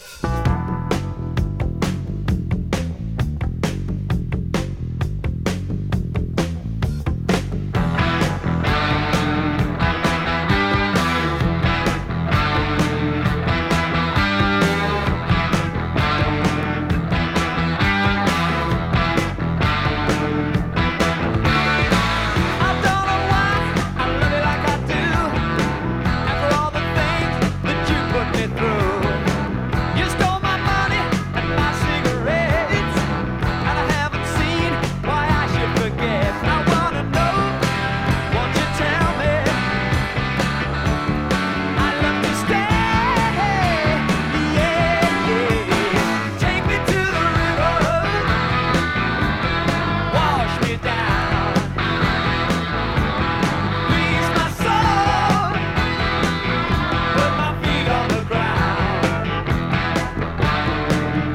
イントロのずっしり重いドラムから引き込まれる、重厚ロックアレンジでプレイしてます！